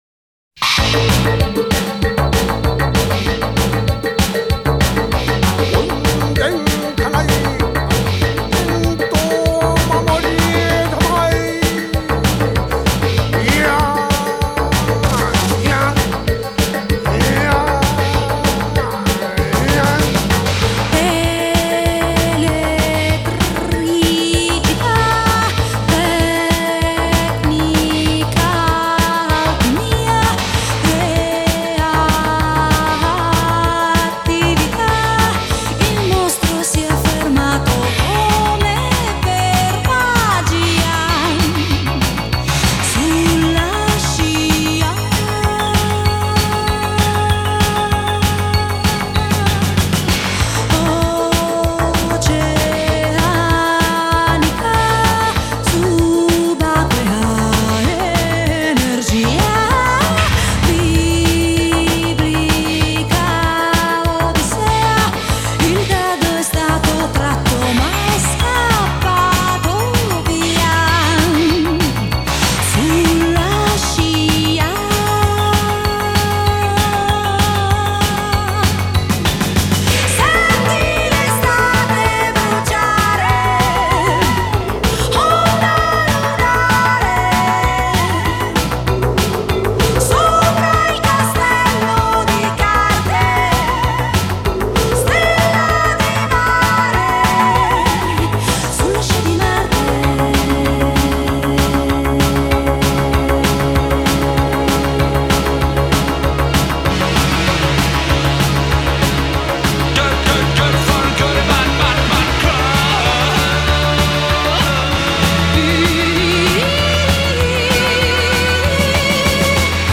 Genre: Pop, Pop-Rock, New Wave, Electronic